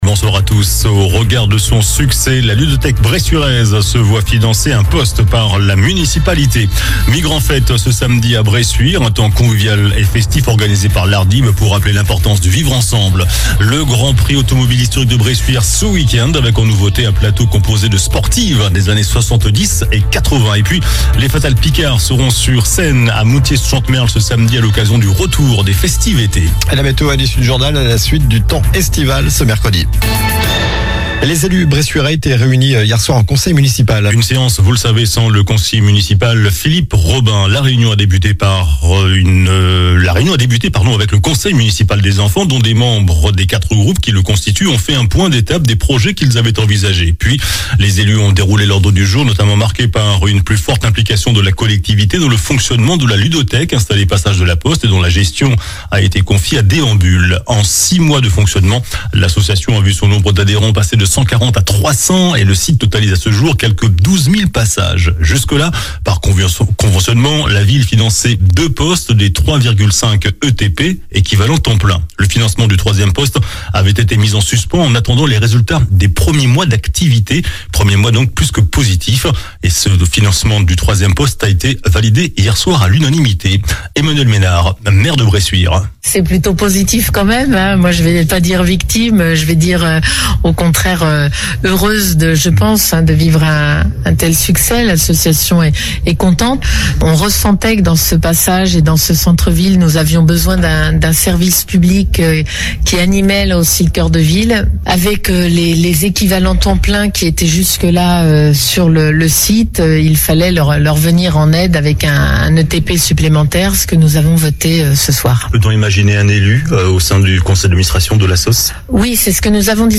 JOURNAL DU MARDI 25 JUIN ( SOIR )